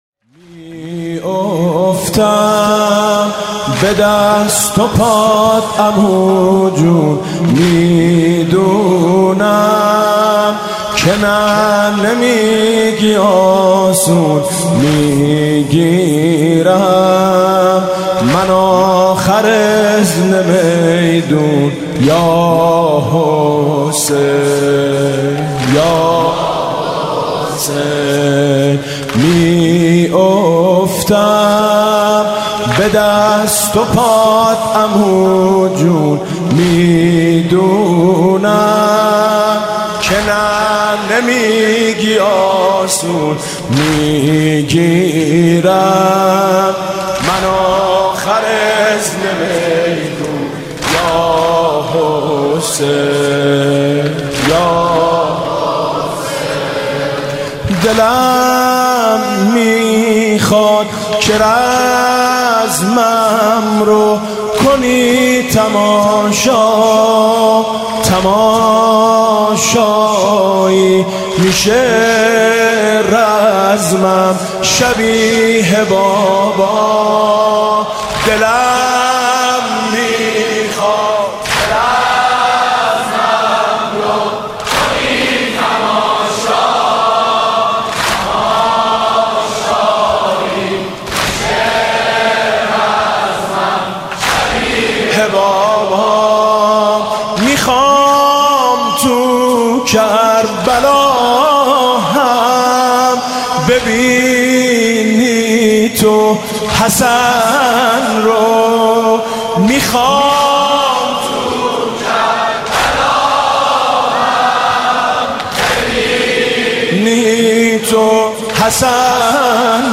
مداحی شب ششم محرم سال ۱۳۹۵ با نوای میثم مطیعی